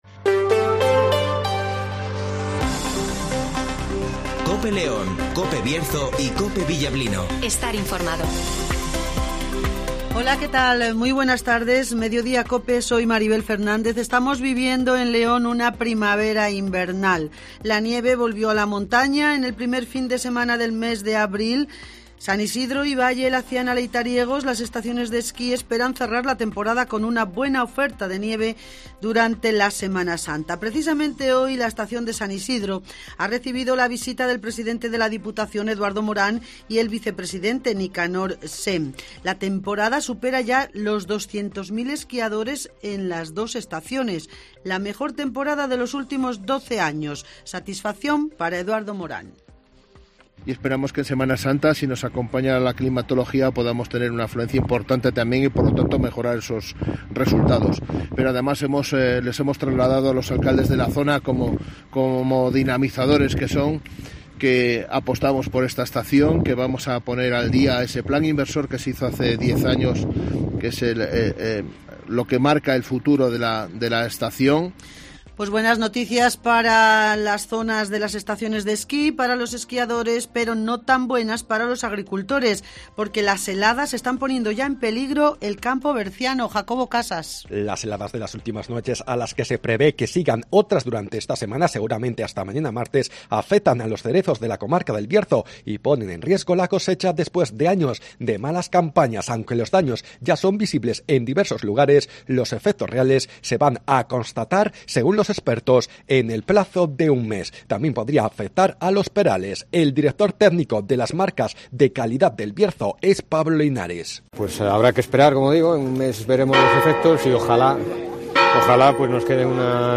Último boletín